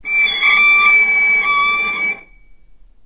vc_sp-D6-ff.AIF